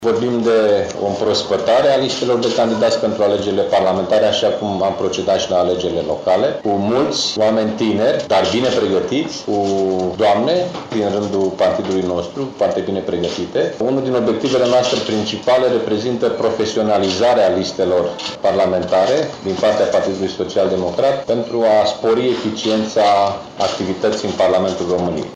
După lucrări, preşedintele formaţiunii, Liviu Dragnea, a susţinut o conferinţă de presă, în care a prezentat principalele subiecte dicutate.